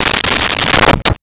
Dresser Drawer Open 02
Dresser Drawer Open 02.wav